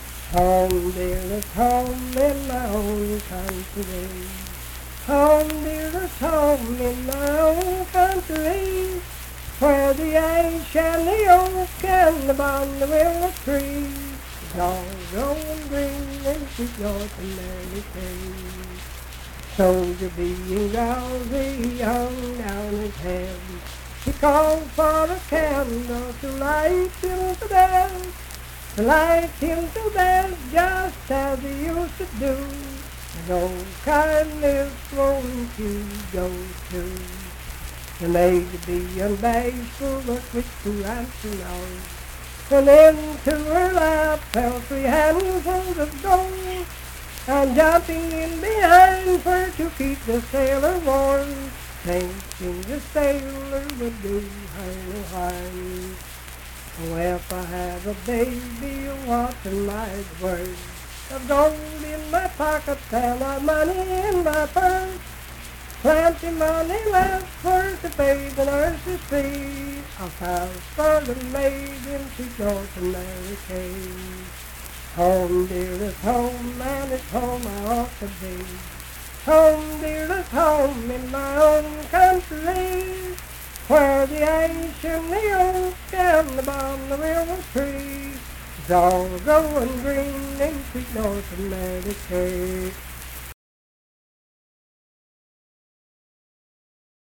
Unaccompanied vocal music
Performed in Ivydale, Clay County, WV.
Voice (sung)